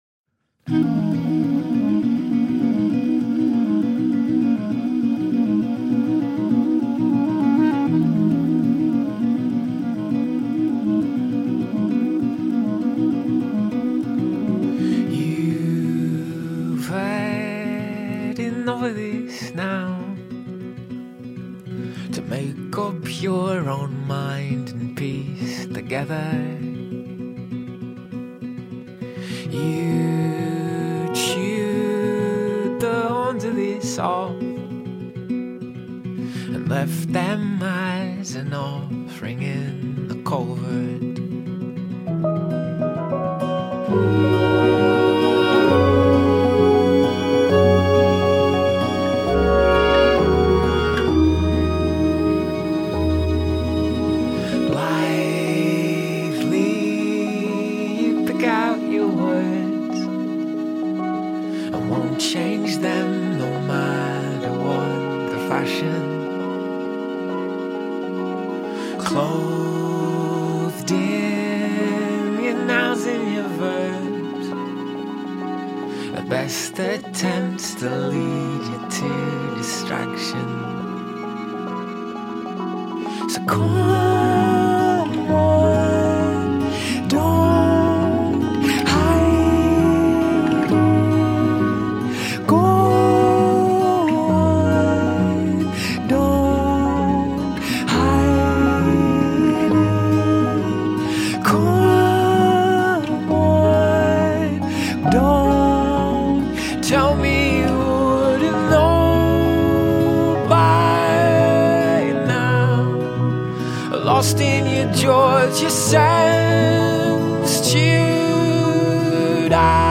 The songs alternately shimmer and float, […]